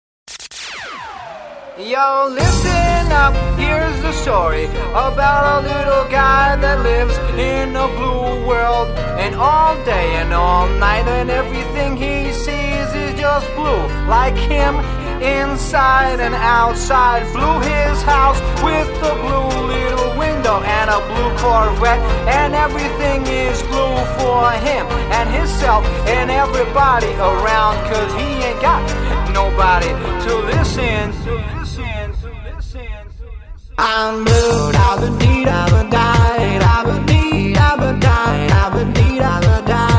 ремиксы
евродэнс , танцевальные